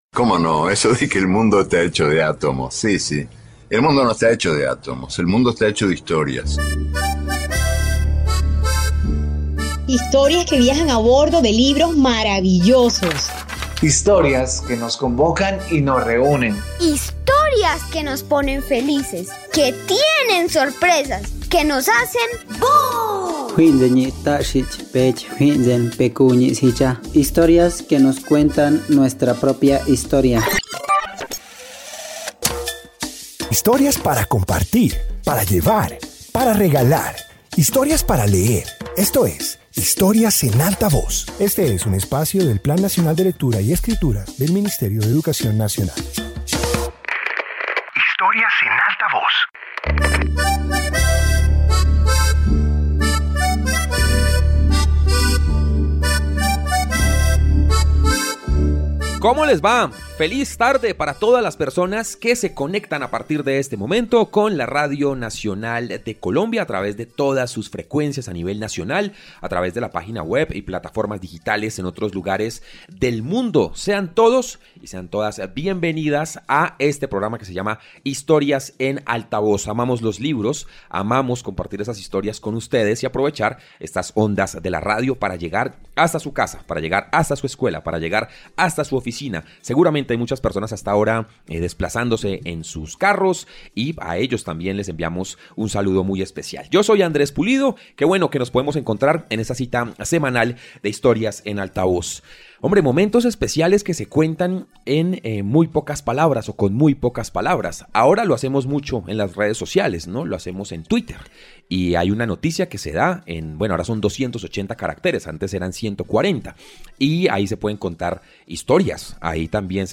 Introducción Este episodio de radio reúne historias breves que sorprenden por su concisión. Presenta microrrelatos que juegan con el lenguaje y muestran cómo una narración corta puede transmitir ideas, emociones y giros inesperados.